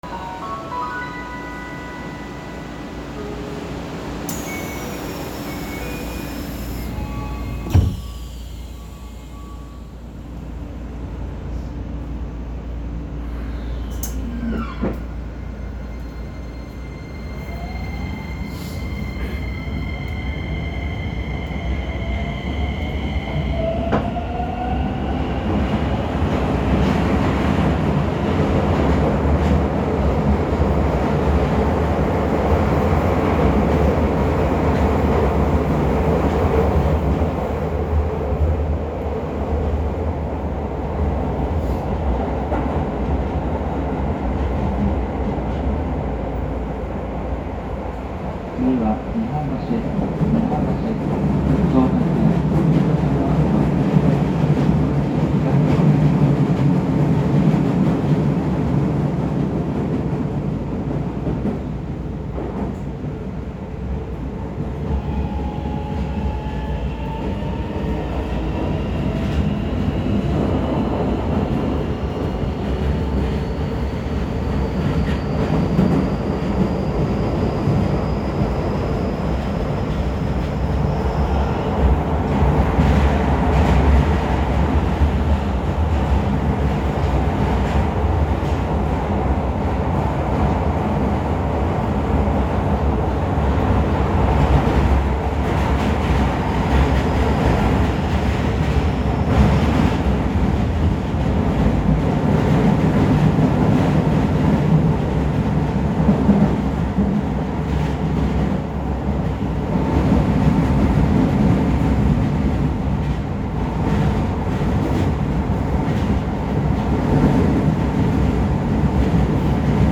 ・3100形走行音
世にも珍しい東洋SiCとなります。…が、東洋IGBTと音の聞こえ方はほぼ変わらず、新鮮味は全くありません。